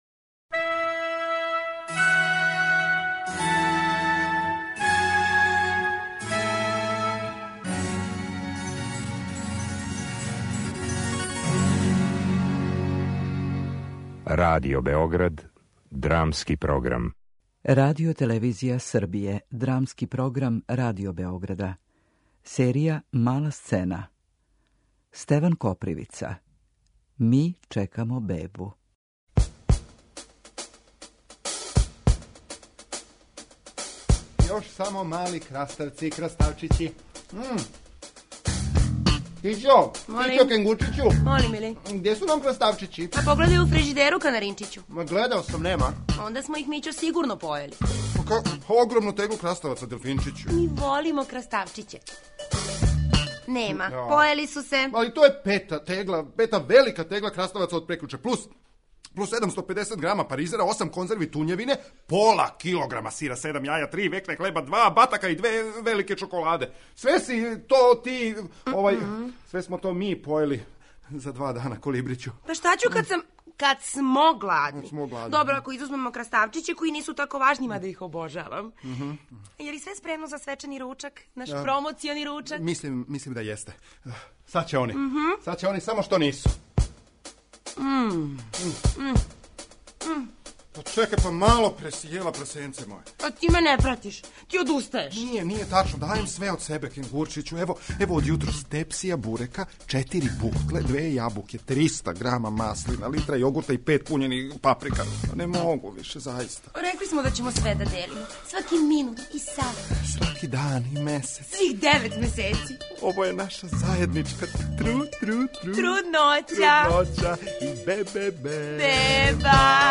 Драмски програм: Мала сцена